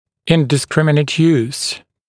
[ˌɪndɪ’skrɪmɪnət juːs][ˌинди’скриминэт йу:с]неразборчивое применение, беспорядочное примеение